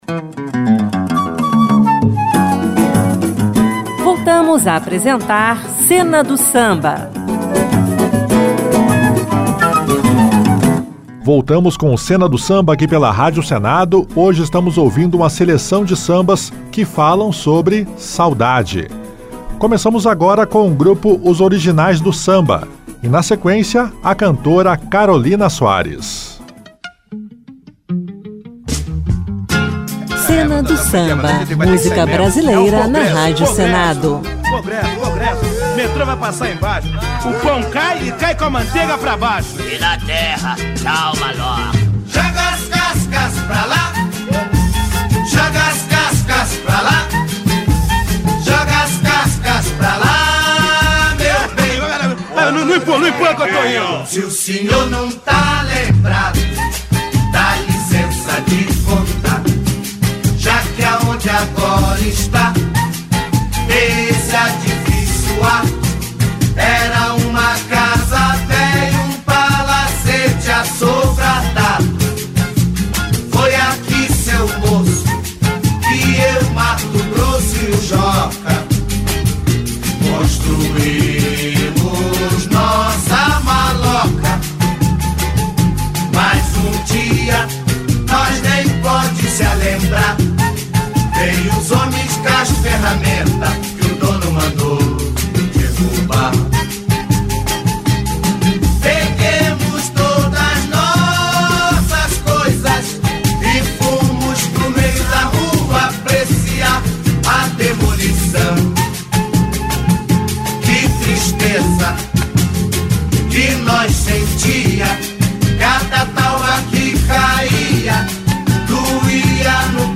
sambas-enredos históricos